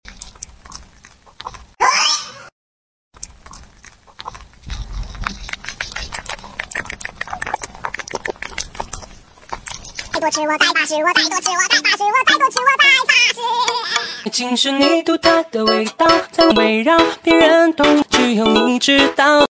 A cute little hamster nibbling sound effects free download
A cute little hamster nibbling with soft and satisfying ASMR sounds